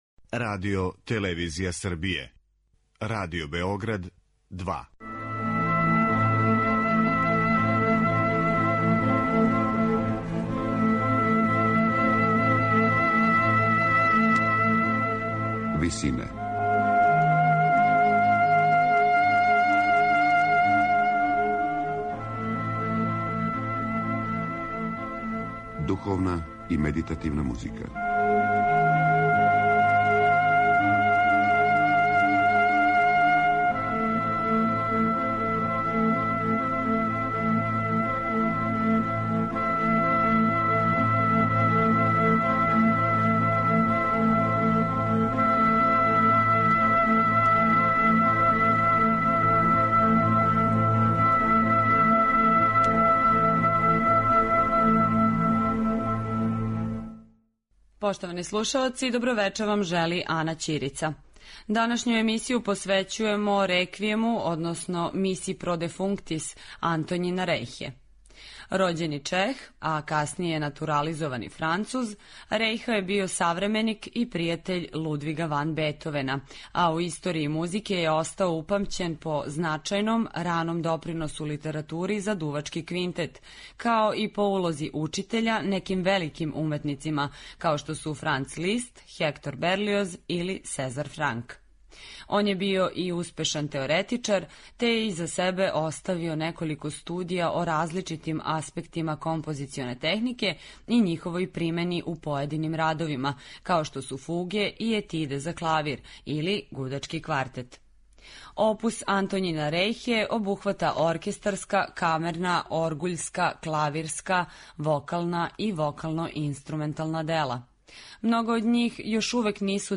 Missa pro defunctis за солисте, оргуље, хор и оркестар, настала је између 1802. и 1808. године, а стилски и временски је позиционирана између монументалних Реквијема Моцарта и Берлиоза...